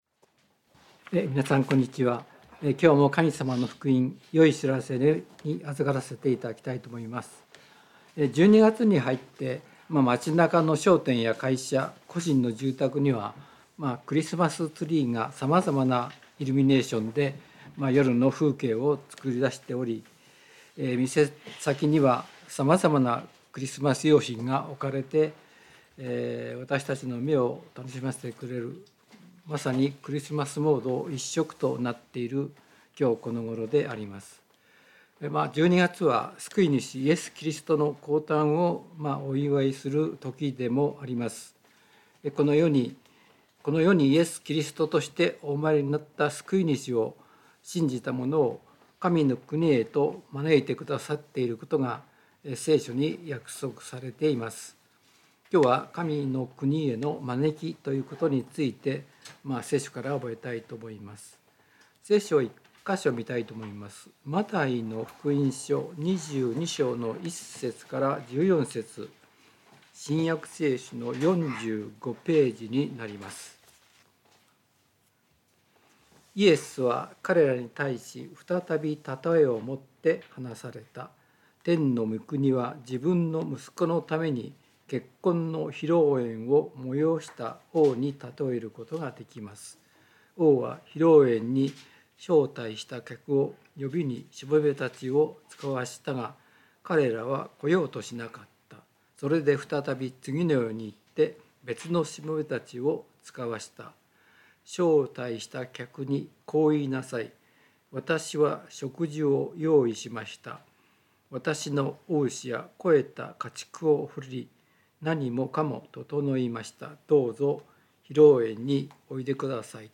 聖書メッセージ No.245